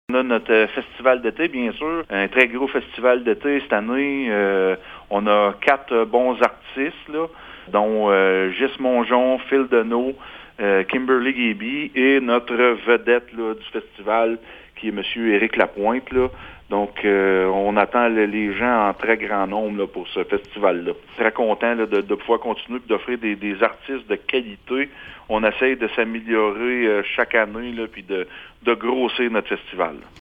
Le maire de Gracefield, Mathieu Caron, est très heureux de la programmation de cette année :